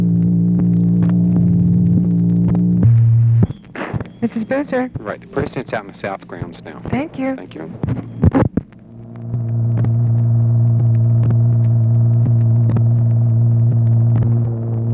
Conversation with UNIDENTIFIED MALE
Secret White House Tapes | Lyndon B. Johnson Presidency